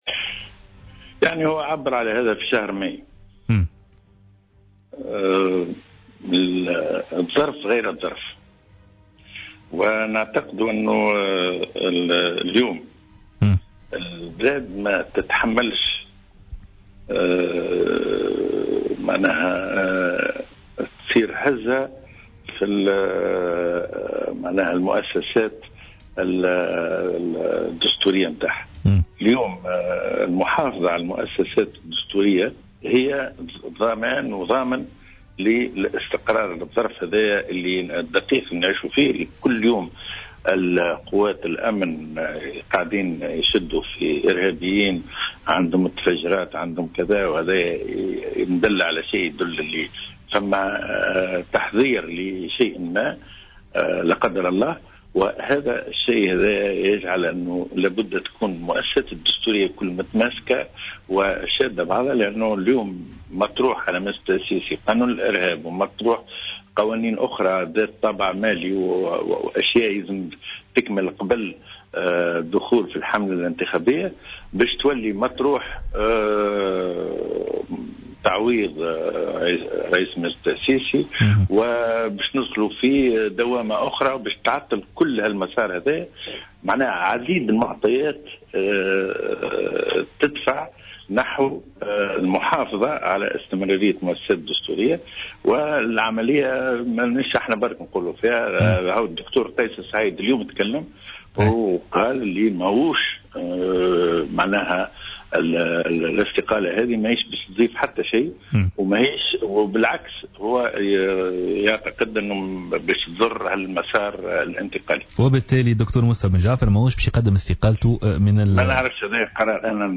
في مداخلة له في برنامج "بوليتيكا"